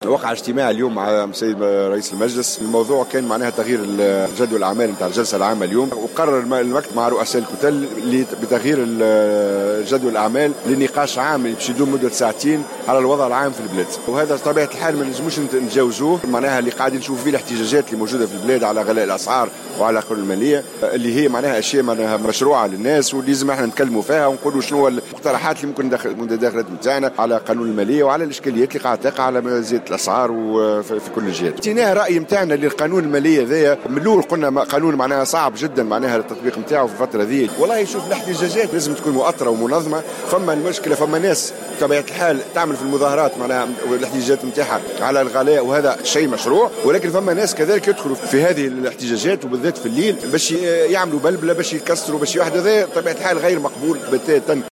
وأضاف في تصريح لمراسل "الجوهرة أف أم" أن الاحتجاج على قانون المالية وغلاء الأسعار أمر مشروع، لكن شريطة أن لا تخرج هذه التحركات عن صفتها السلمية وتتحول إلى أعمال نهب وتخريب.